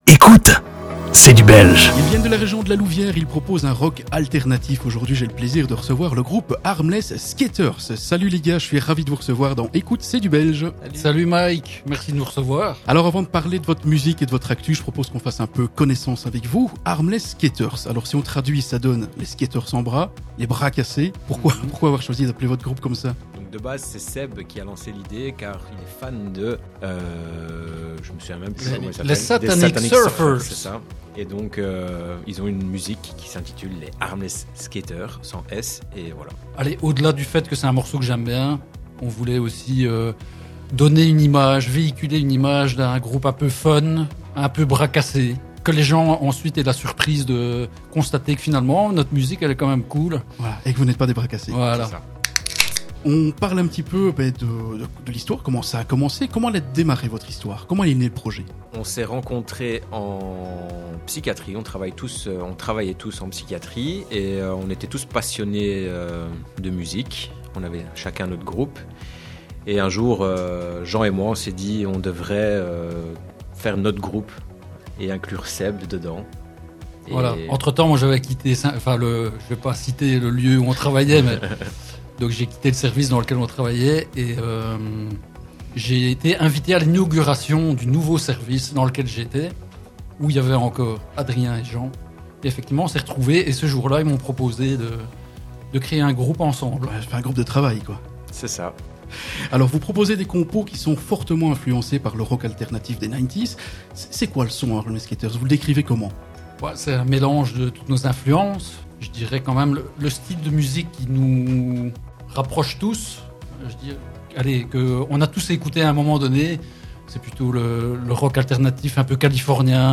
Rencontre et interview du groupe ARMLESS SKATERS